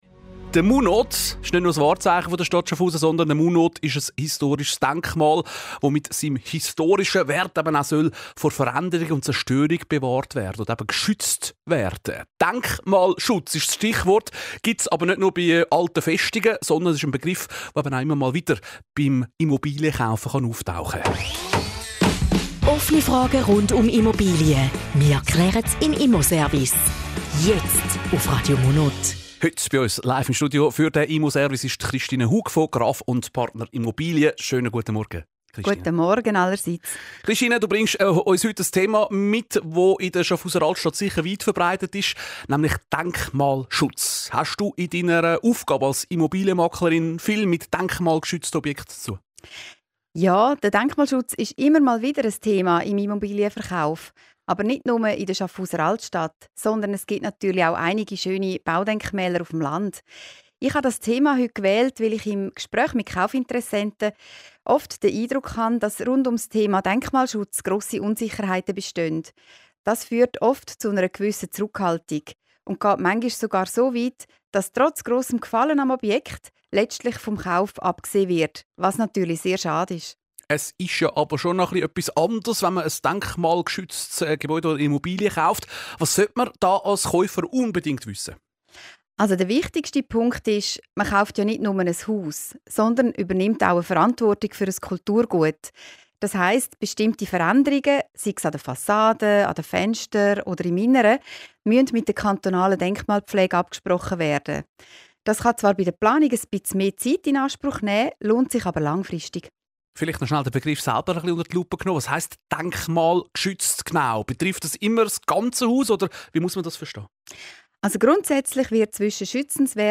Zusammenfassung des Interviews zum Thema "Denkmalschutz und Wohneigentum":